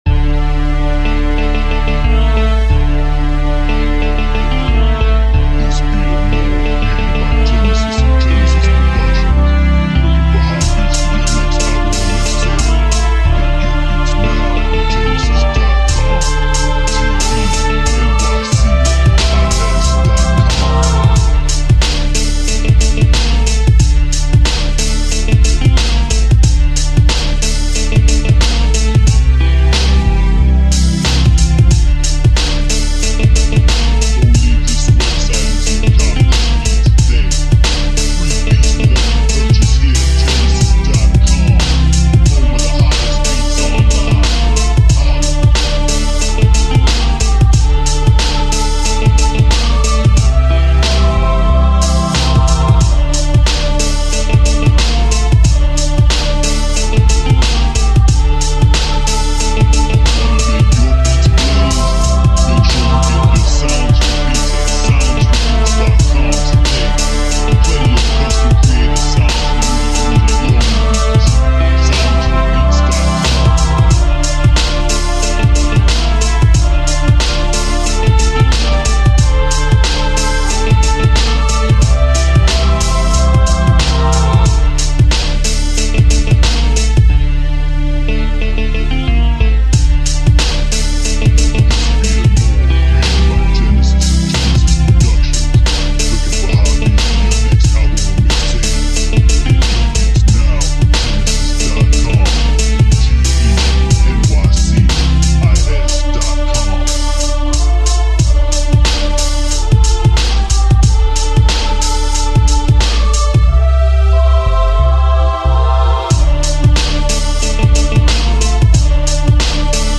Piano Flava East Coast Beat